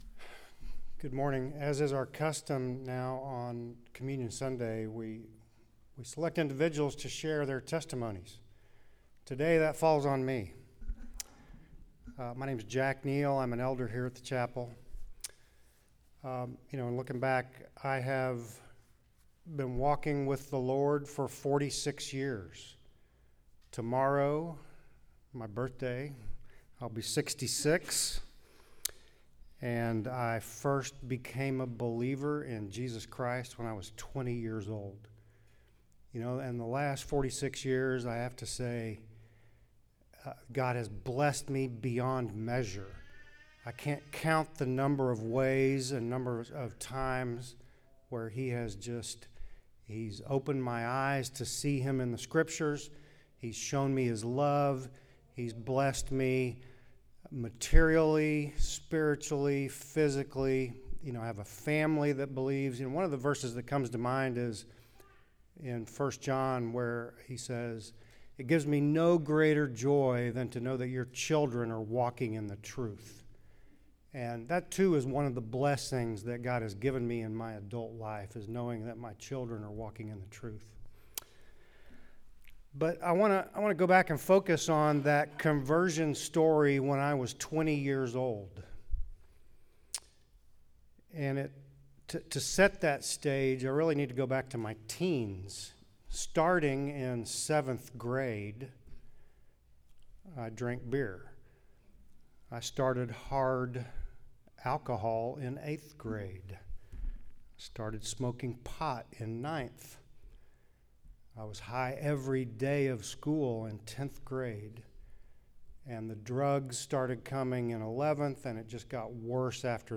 Topic: Testimonies